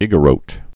(ĭgə-rōt, ēgə-)